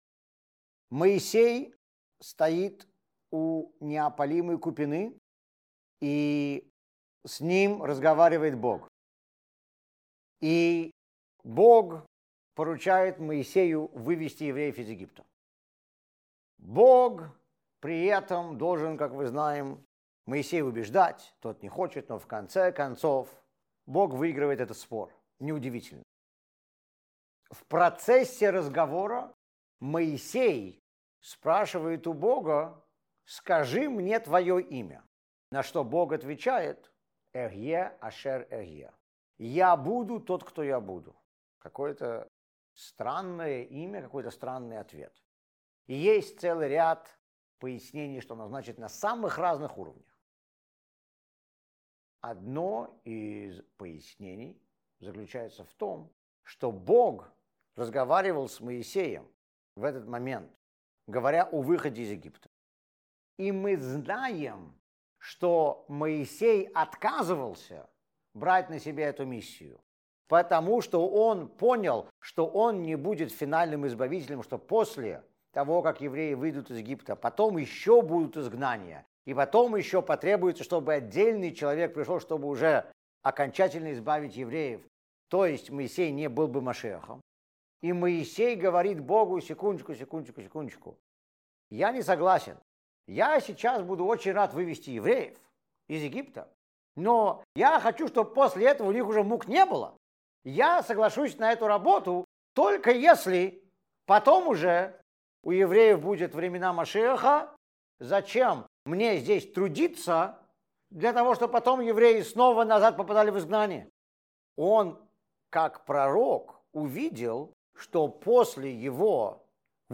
Уроки по Торе